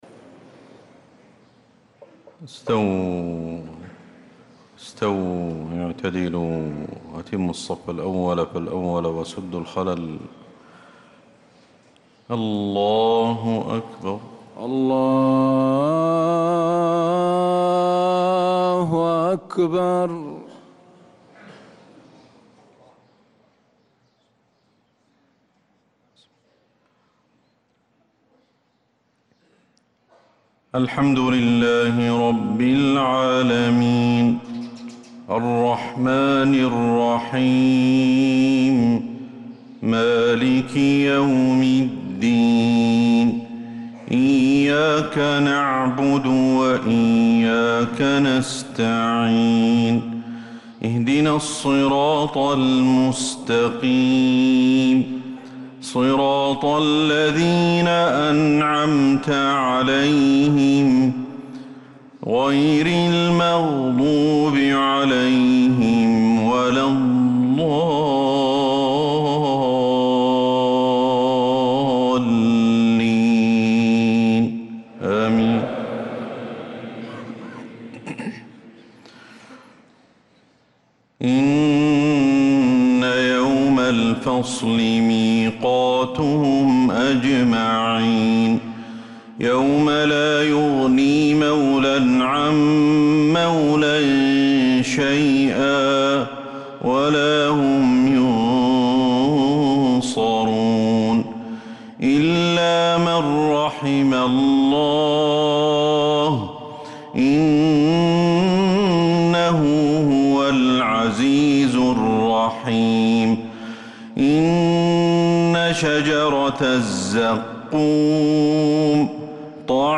صلاة المغرب
تِلَاوَات الْحَرَمَيْن .